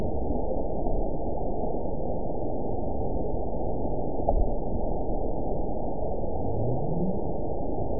event 914155 date 04/29/22 time 05:03:15 GMT (3 years ago) score 9.37 location TSS-AB05 detected by nrw target species NRW annotations +NRW Spectrogram: Frequency (kHz) vs. Time (s) audio not available .wav